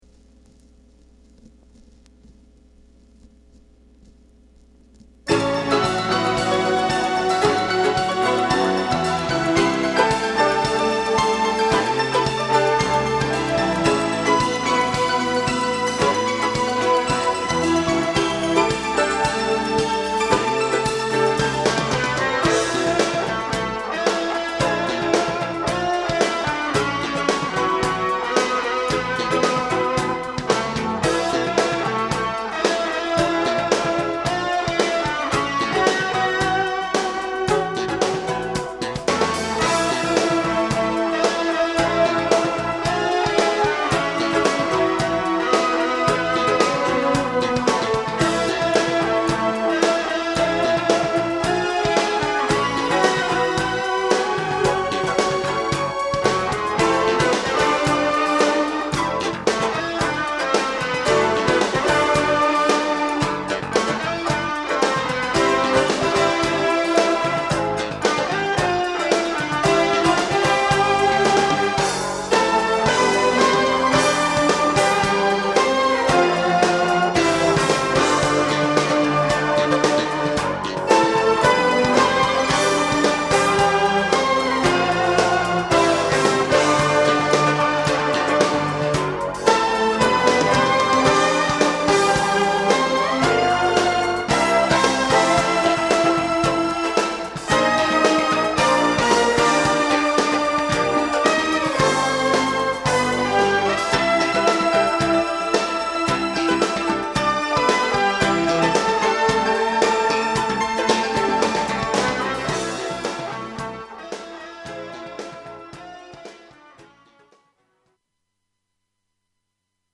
Genre: Jazz Fusion
Studio Jive（東京）にて1988年1月〜2月に録音。
開放的なギターのトーンと軽やかなリズムが気持ちよく走る。